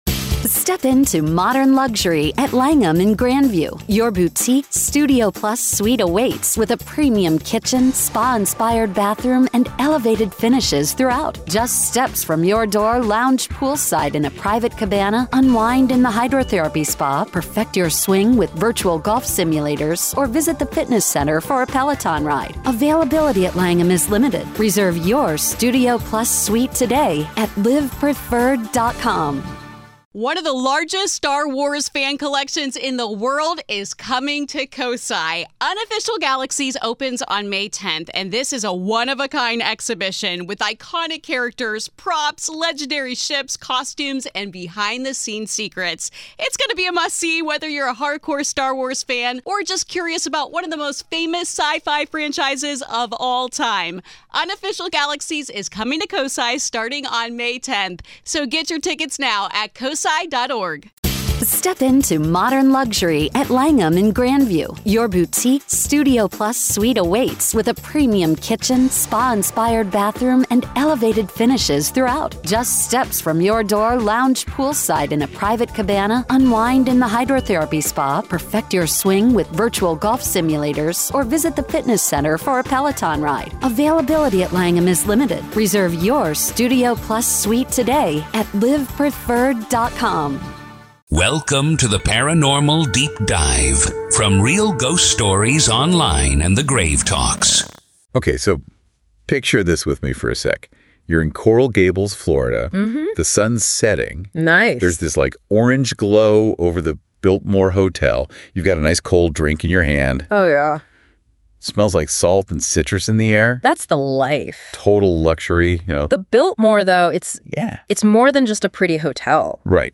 Deep Dive DISCUSSION!